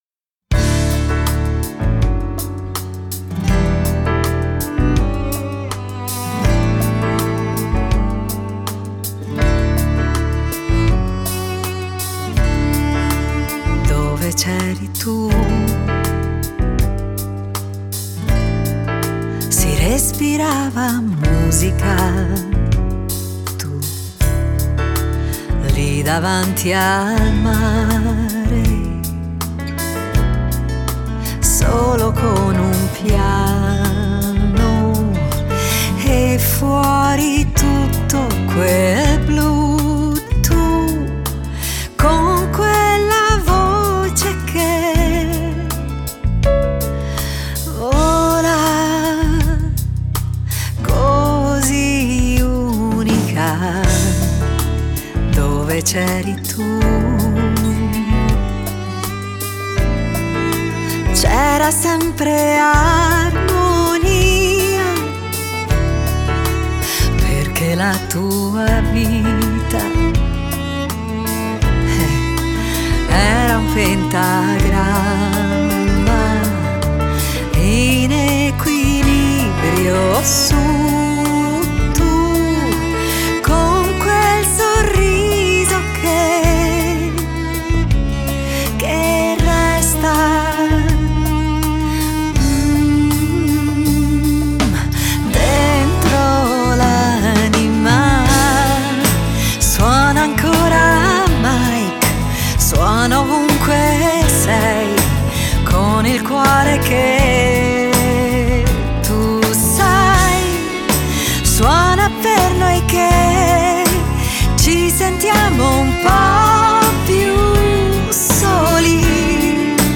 Genre: Pop Lounge, Bossa